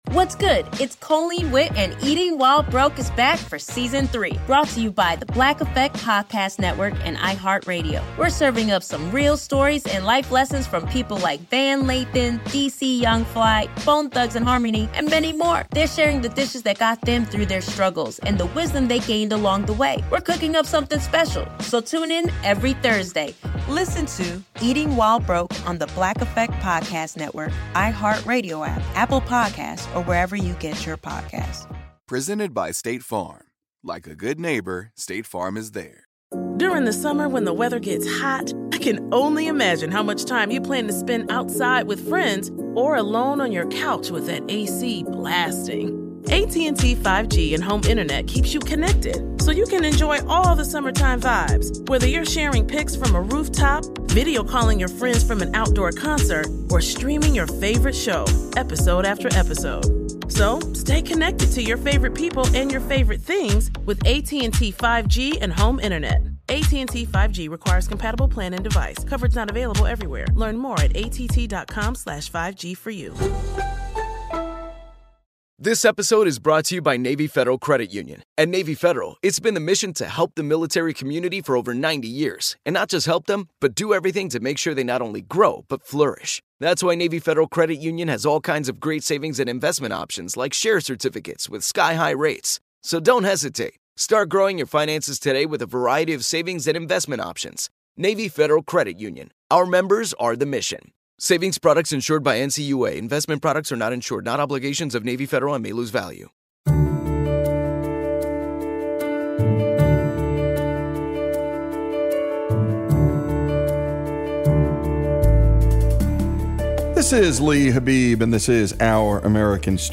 How he defined success was unique and central to his philosophy. This story is told by Coach Wooden himself, his family, friends, and players.